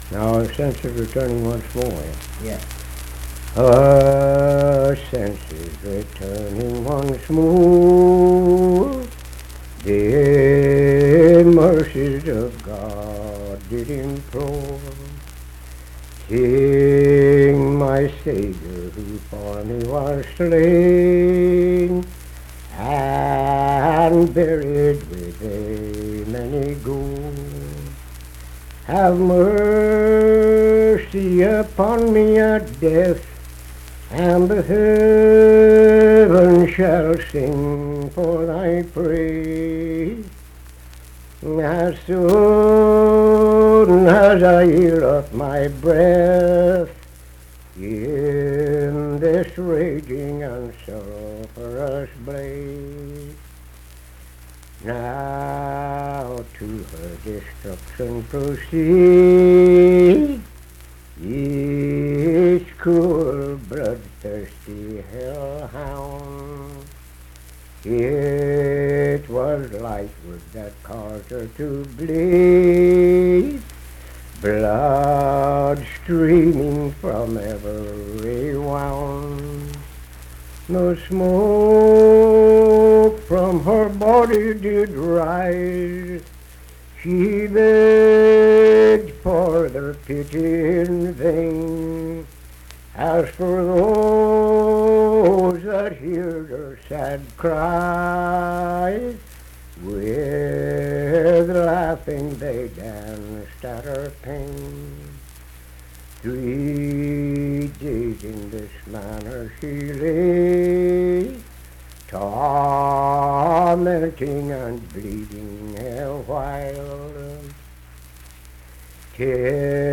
Unaccompanied vocal music performance
Verse-refrain 8(8).
Voice (sung)
Nicholas County (W. Va.), Birch River (W. Va.)